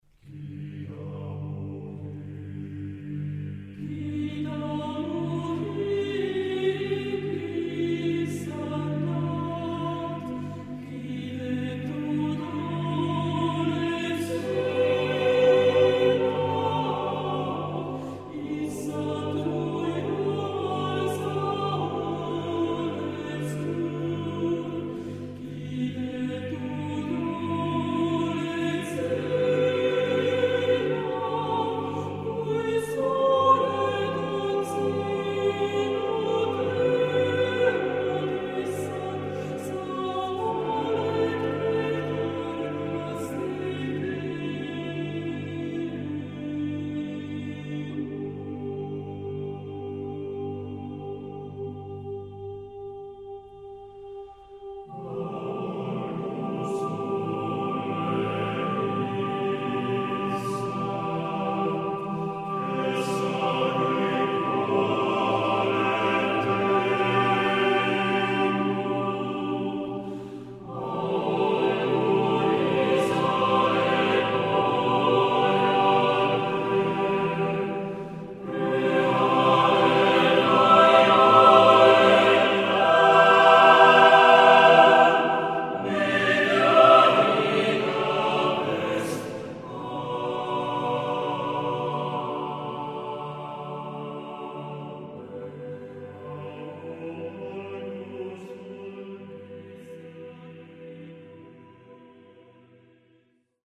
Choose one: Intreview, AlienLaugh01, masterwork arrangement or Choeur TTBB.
Choeur TTBB